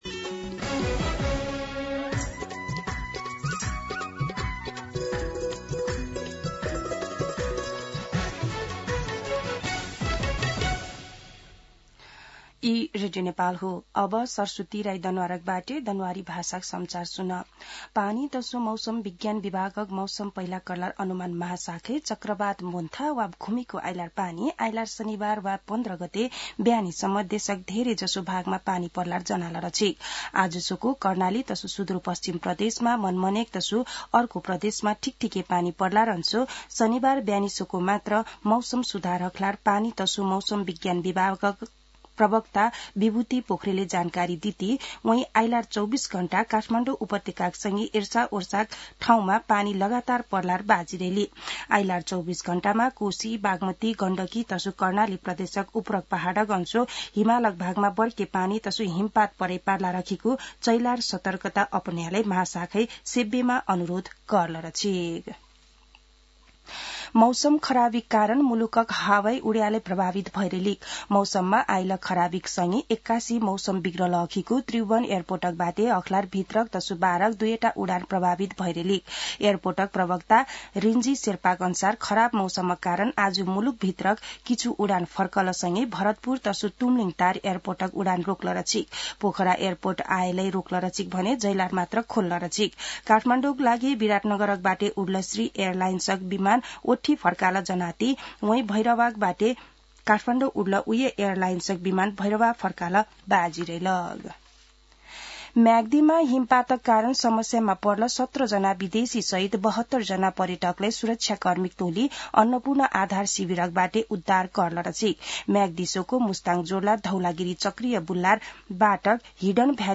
दनुवार भाषामा समाचार : १३ कार्तिक , २०८२
Danuwar-News-7-13.mp3